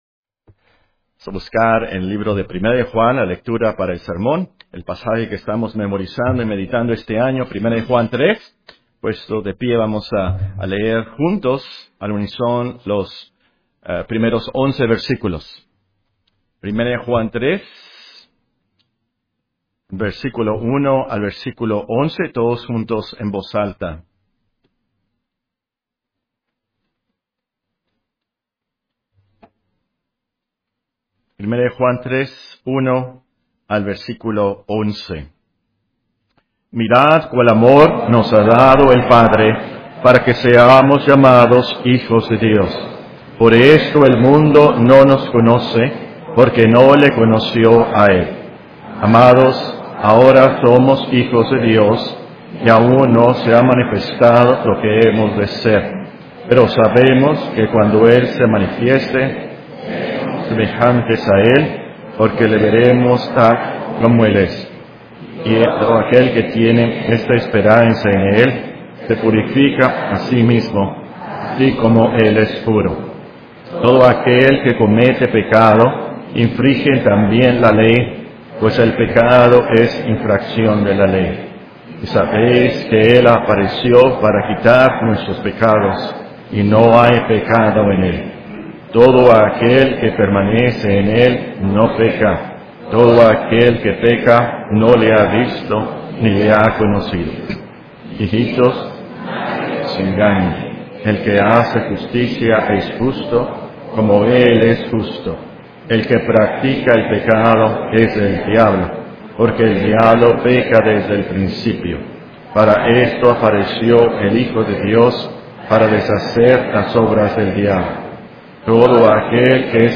Serie de sermones 1 Juan 3